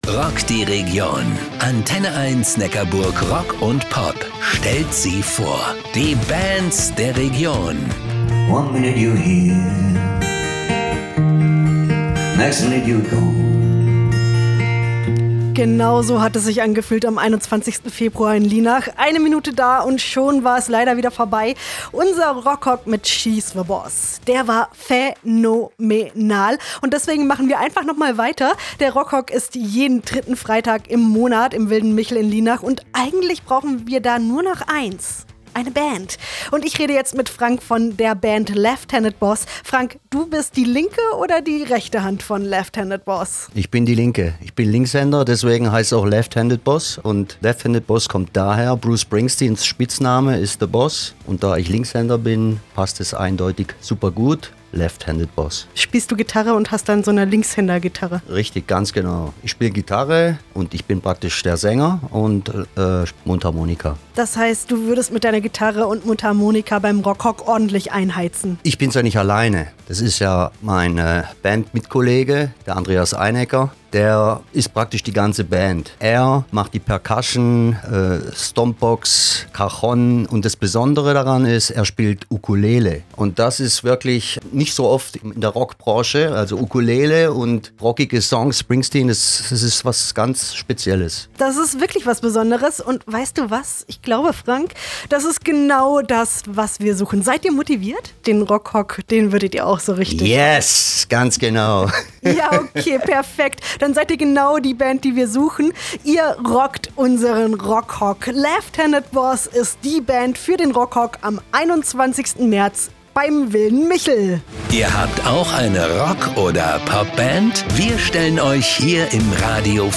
Interview Radio Antenne 1 Neckarburg Rock & Pop – „Rockhock“ Zum Wilden Michel